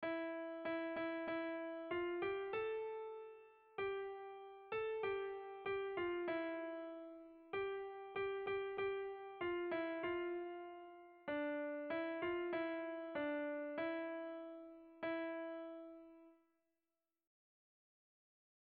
Sentimenduzkoa
Lauko txikia (hg) / Bi puntuko txikia (ip)
AB